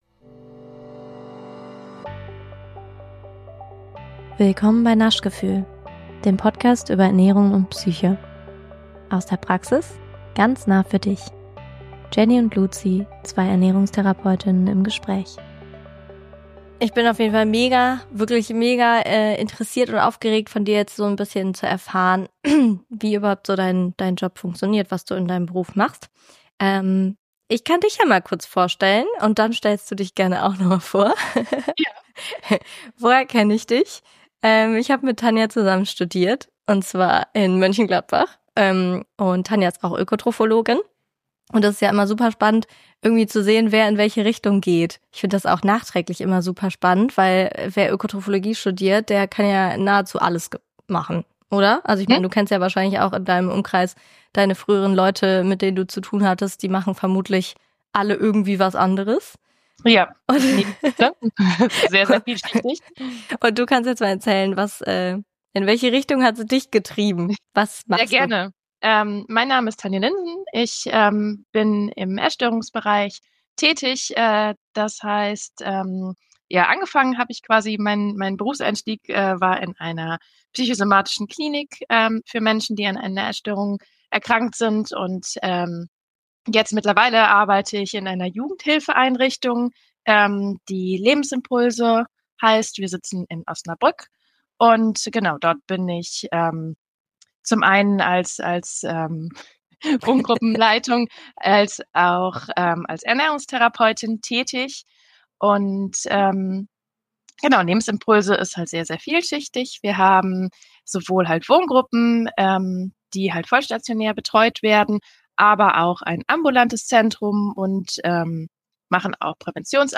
interviewen